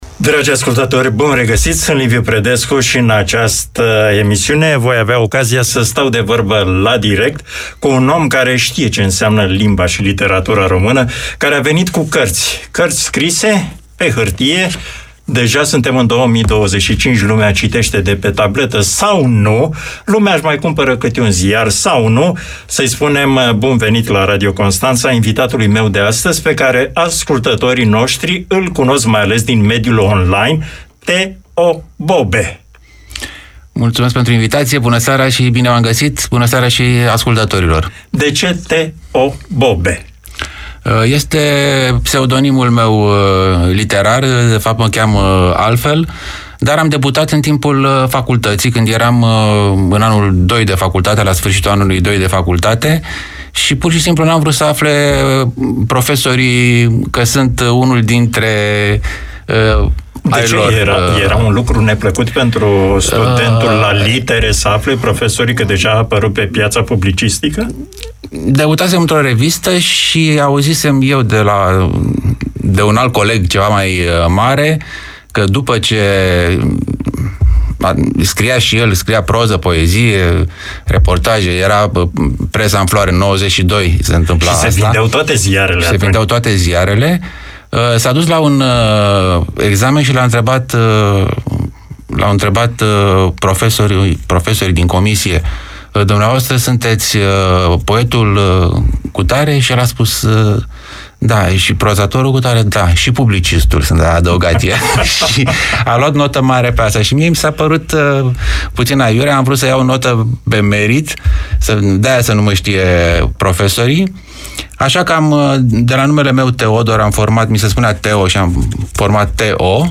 un interviu cu și despre viața unui iubitor de Litere